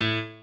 piano7_31.ogg